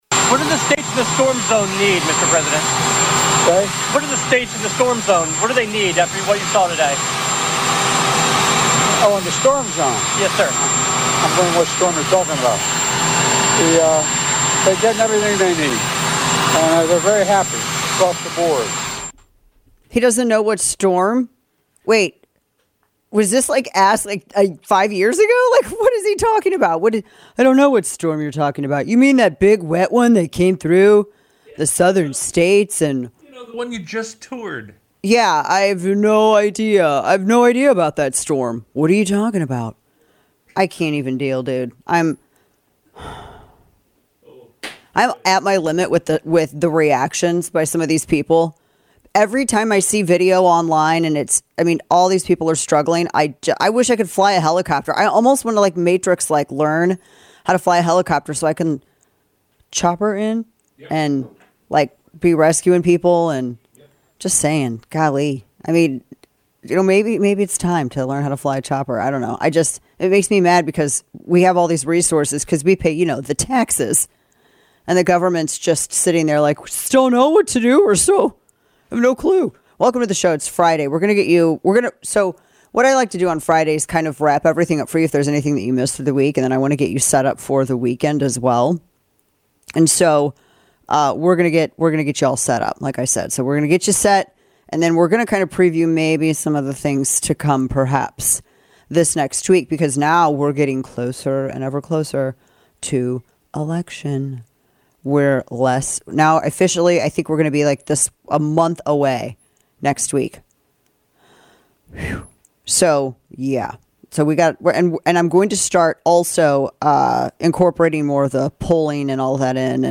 FEMA fails to find extra funds for Americans in need following Hurricane Helene because of migrant funding. Florida Gov. Ron DeSantis joins us to discuss Florida's Helene recovery efforts, FEMA failures, the Longshoremen's port strike and more.